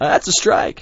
b_nowthatsaStrike.wav